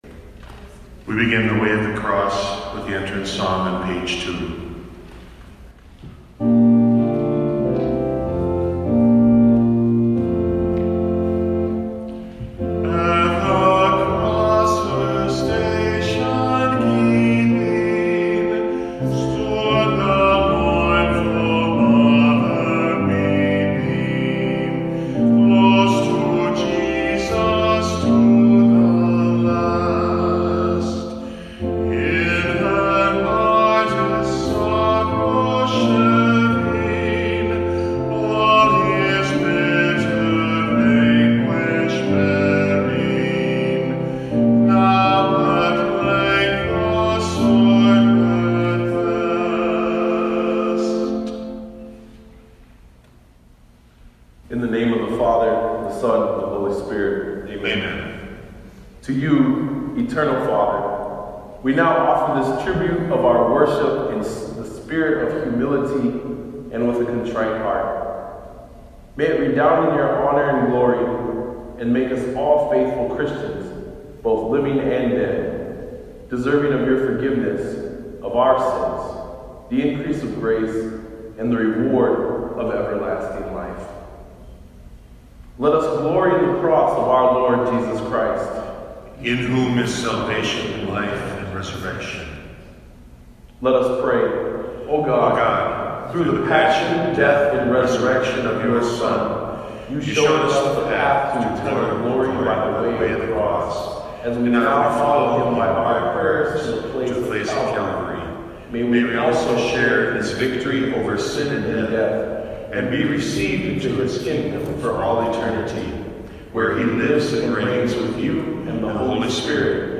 Song: At the Cross Her Station Keeping (additional verse sung after each station)